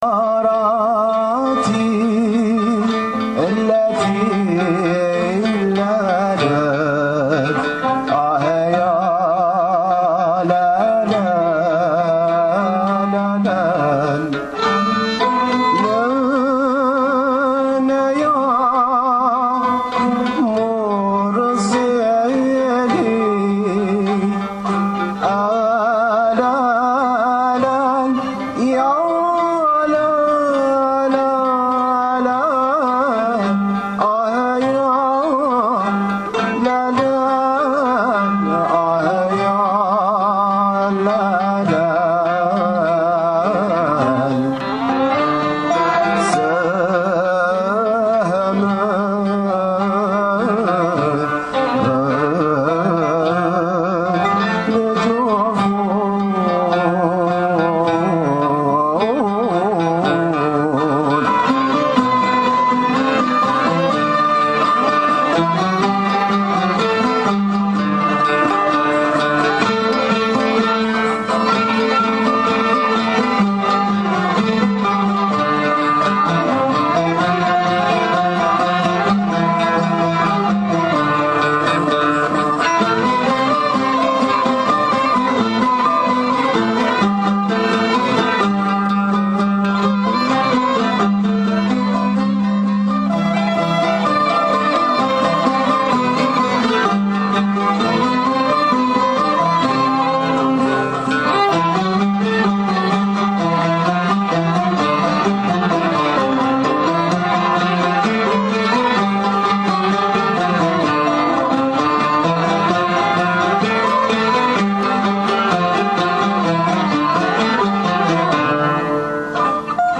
Musique Arabo-Andalouse"
air sur le site magnifique du Baron d'Erlanger � Sidi Bousa�d. Nous avons